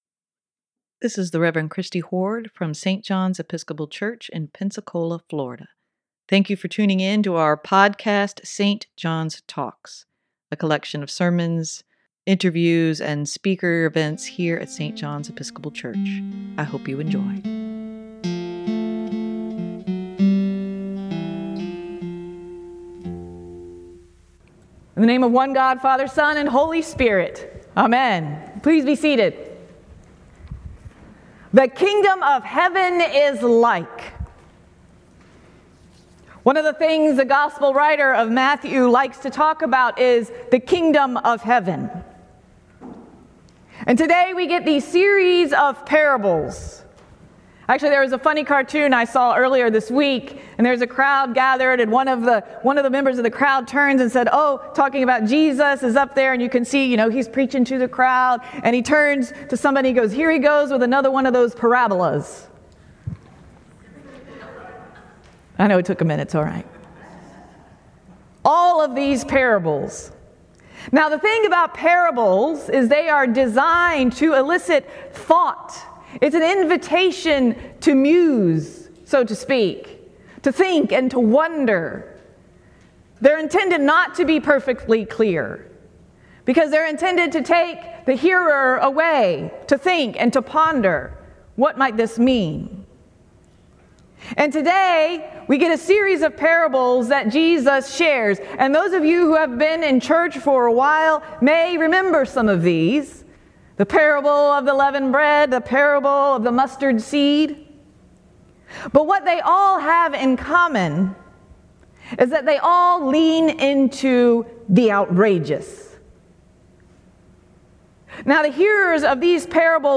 Sermon for July 30, 2023: Nothing can separate us from the love of God - St. John's Episcopal Church
sermon-7-30-23.mp3